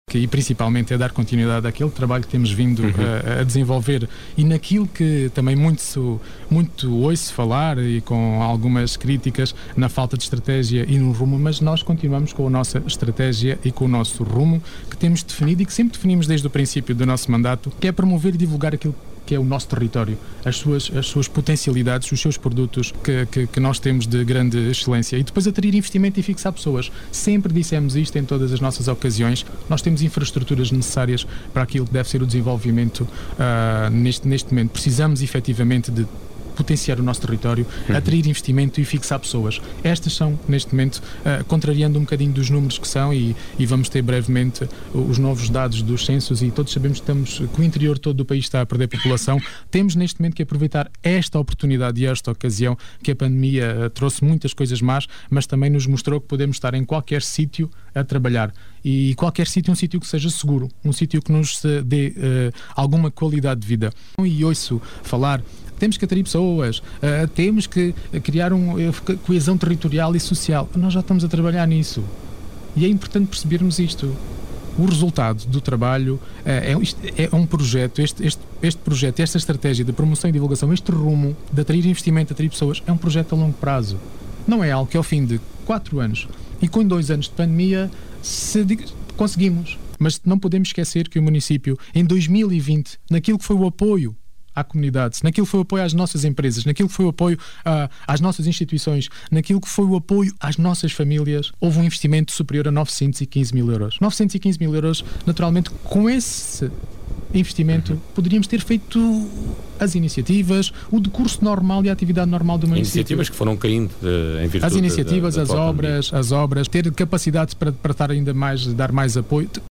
O cabeça de lista da CDU, em entrevista na Rádio Vidigueira, no programa À Conversa Com, da passada sexta-feira, afirma que a “estratégia e o rumo” estão definidos desde o inicio do mandato, e passa por “promover e divulgar o território” e “atrair investimentos e fixar pessoas”.